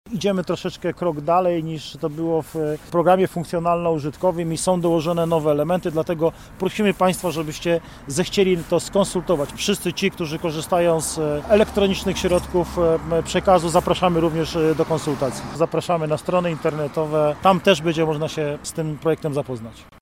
Ci którzy nie mogli uczestniczyć w nim osobiście mogą jednak wyrazić swoje zdanie, przekonuje prezydent Sieradza Paweł Osiewała.